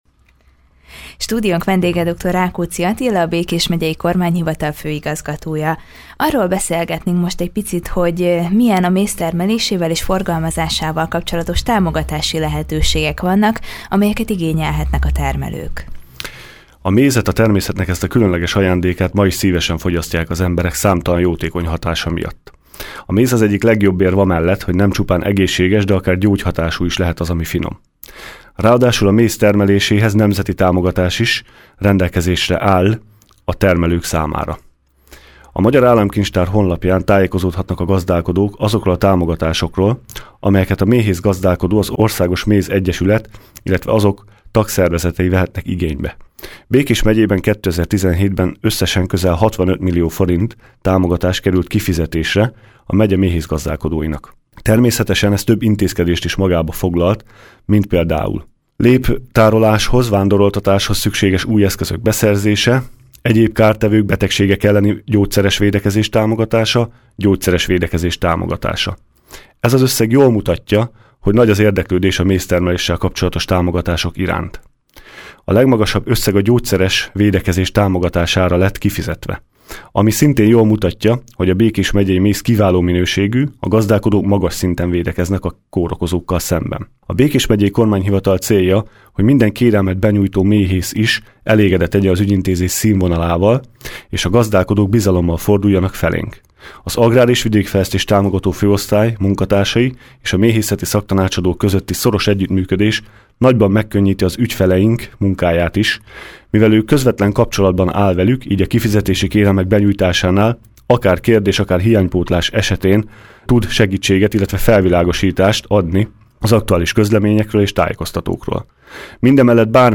Ez az összeg jól mutatja, hogy nagy az érdeklődés a mézzel kapcsolatos támogatások iránt. 2018-ban is több támogatás közül választhatnak a méhészek. Ezzel kapcsolatban beszélgettünk Dr. Rákóczi Attilával, a Békés Megyei Kormányhivatal főigazgatójával.